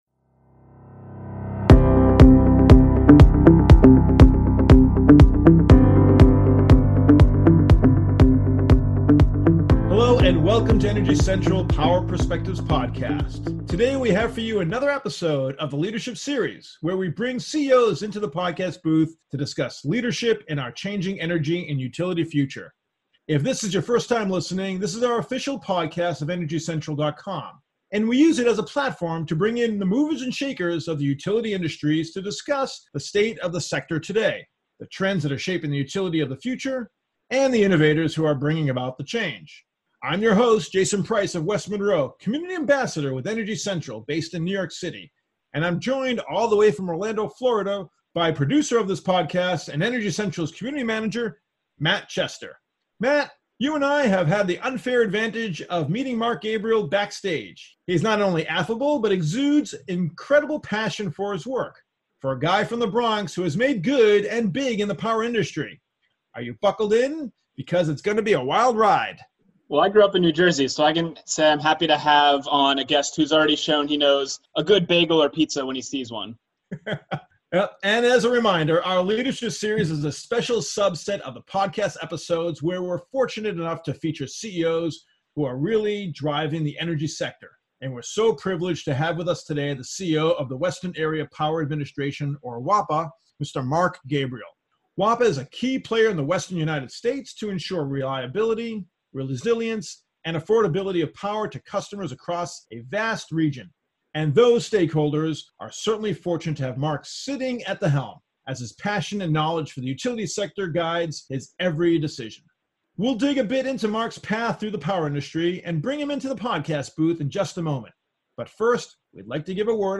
As the latest entry into our Leadership Series, the Energy Central Power Perspectives Podcast was joined by Mark Gabriel, the outgoing CEO of Western Area Power Administration.